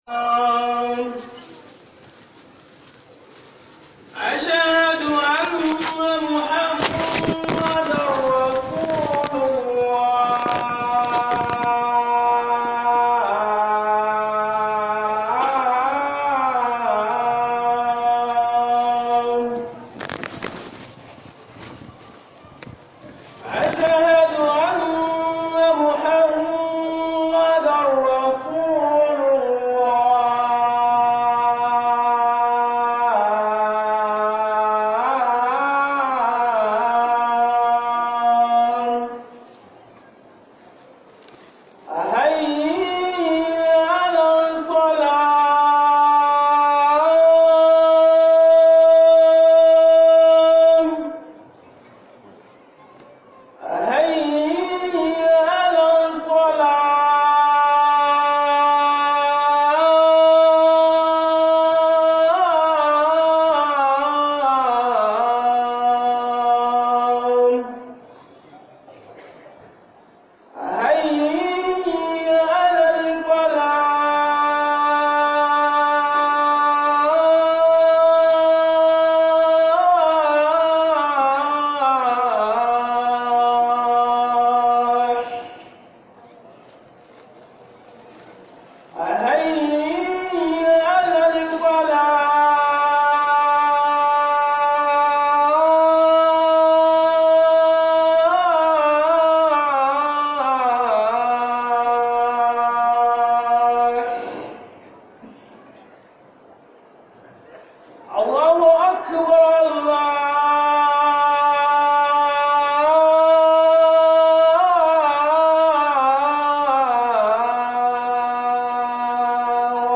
HUDUBA MARKAZ (3) (2) - HUƊUBOBIN JUMA'A